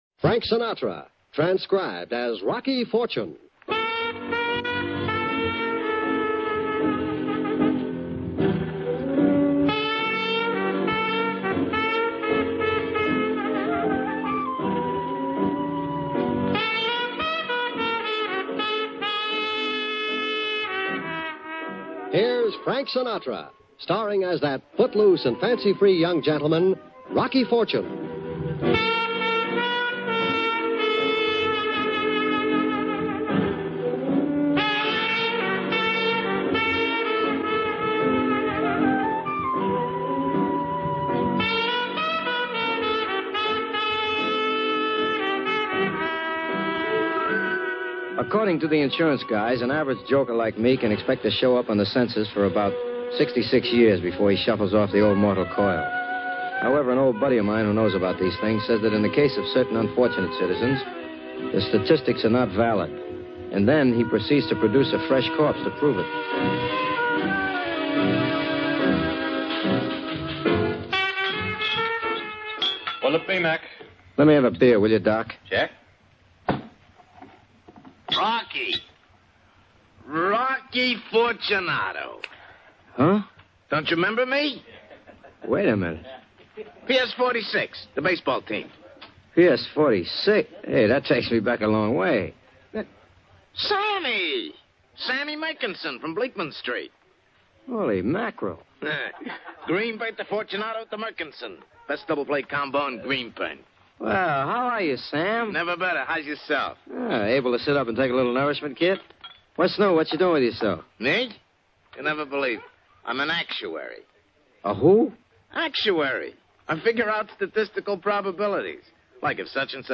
Rocky Fortune, Starring Frank Sinatra